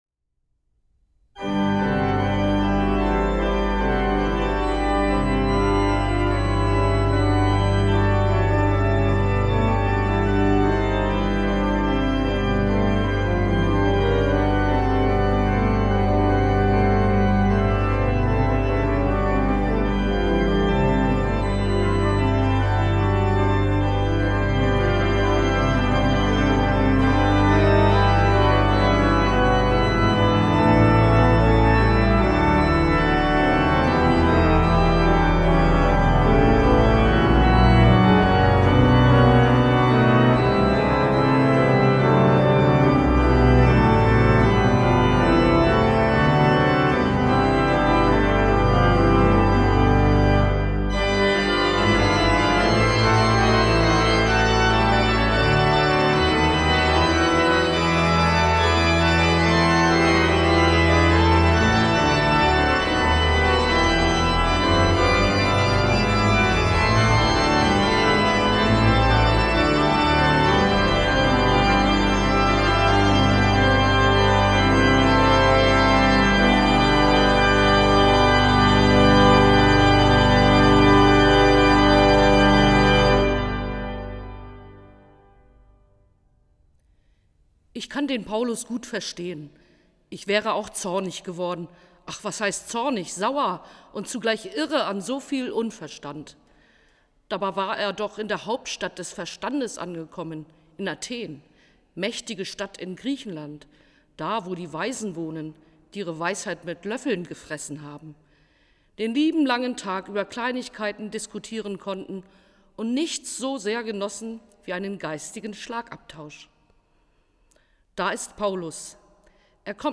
Musik: Choralvorspiele von Karl Hasse und Karl Köhler zum Lied „Gott ist gegenwärtig“ (EG 165) bzw. „Wunderbarer König“ (EG 327)